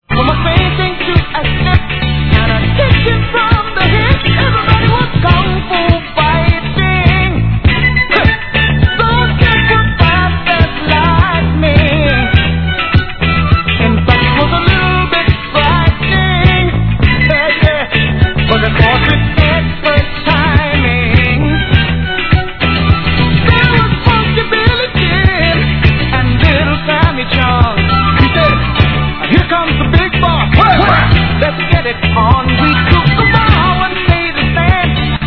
Tag       DANCE CLASSICS OTHER